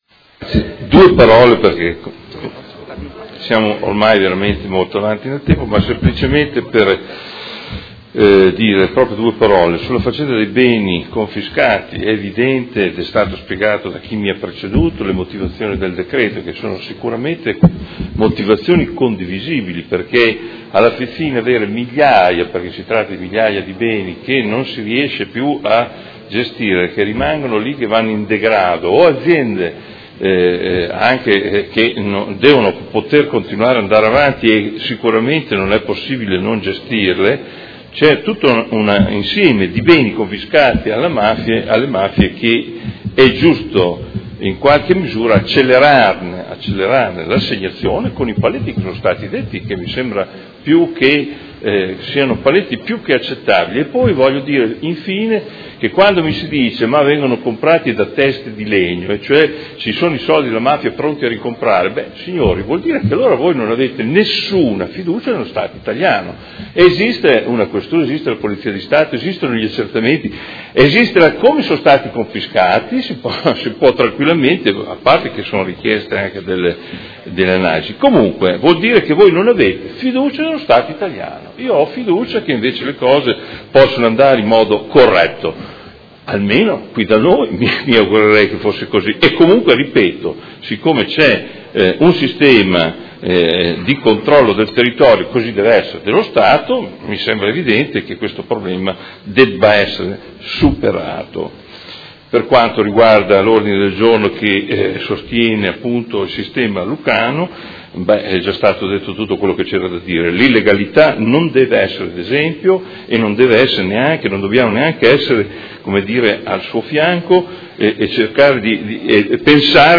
Seduta del 29/11/2018 Dibattito congiunto su Ordine del Giorno Prot. Gen. n.158233 e Ordine del Giorno Prot. Gen. n. 168296 e Ordine del Giorno Prot. Gen. n. 175105